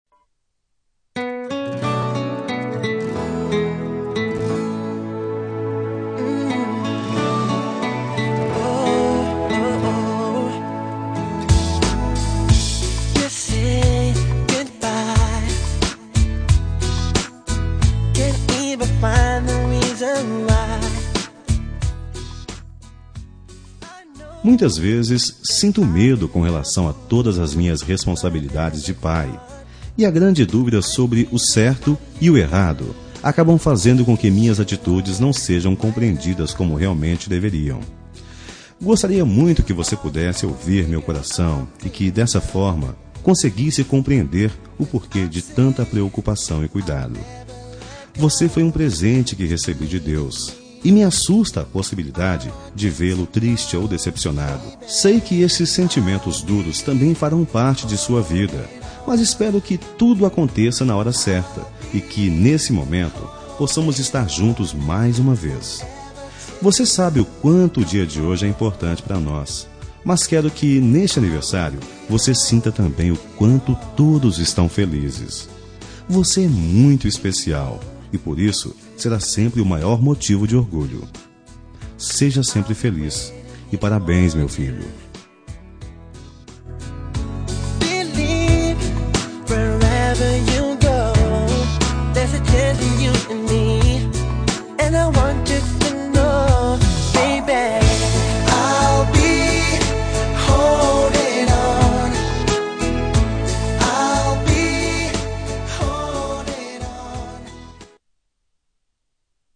Telemensagem de Aniversário de Filho – Voz Masculina – Cód: 1859